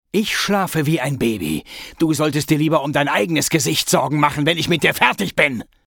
Goin' Downtown Sample 6 Datei herunterladen weitere Infos zum Spiel in unserer Spieleliste Beschreibung: Gangster Khan nimmt Jake in einer Szene gefangen. Ihr hört die Stimme von Antonio Banderas.